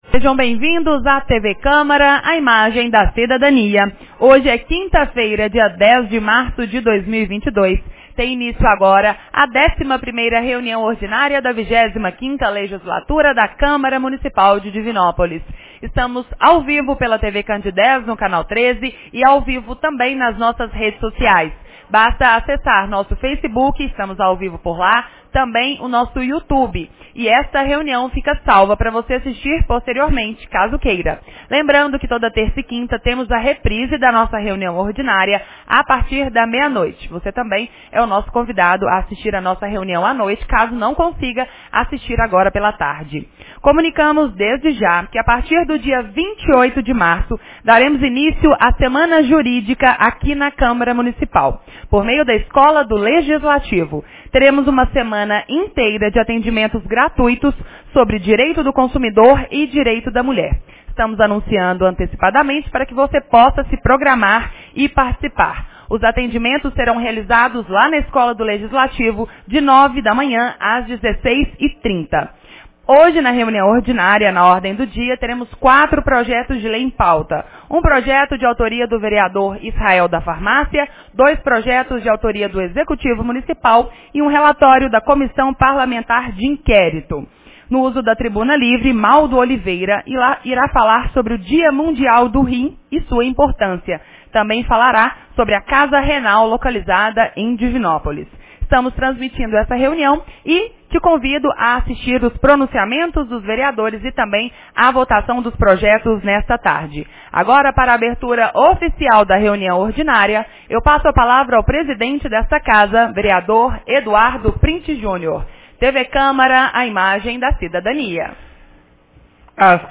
11ª Reunião Ordinária 10 de março 2022